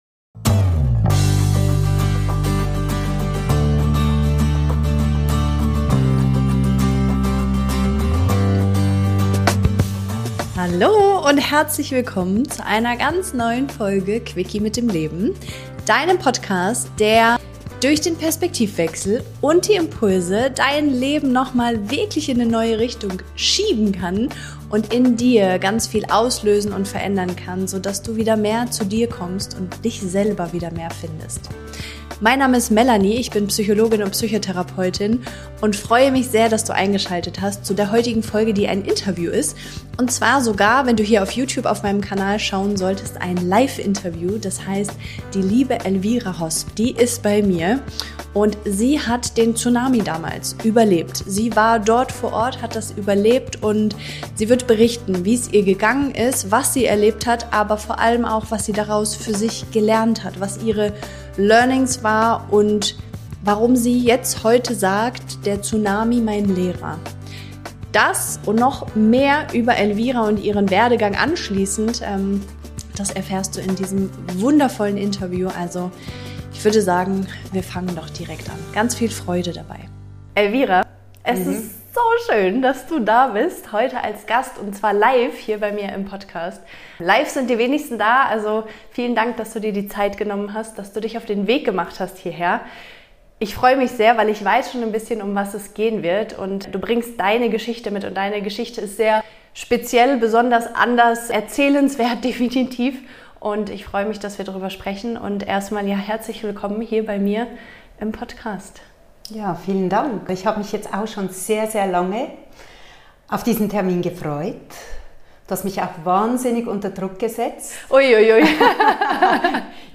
Du erfährst, was ein traumatisches Erlebnis mit uns macht, warum Kontrolle eine Illusion ist und wie echte Heilung in der Tiefe beginnt. Ein Gespräch über Schmerz, Hingabe und die Rückverbindung zum Leben.